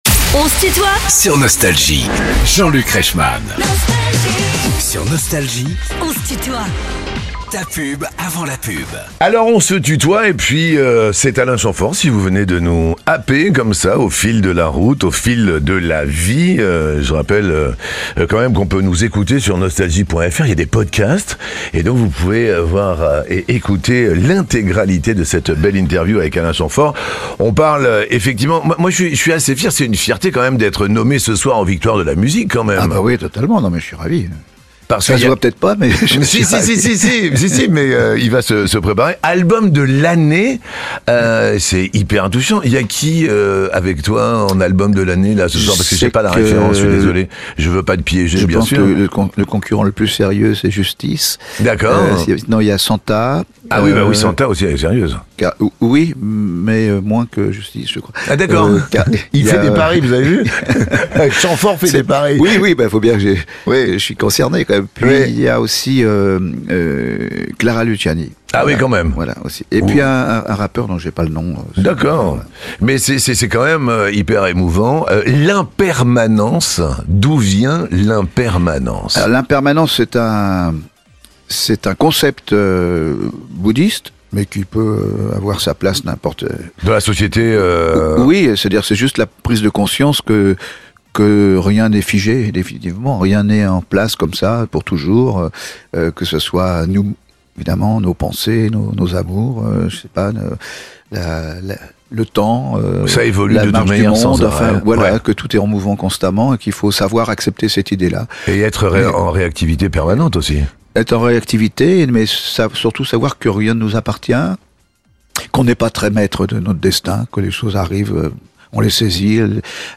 Alain Chamfort est l'invité de "On se tutoie ?..." avec Jean-Luc Reichmann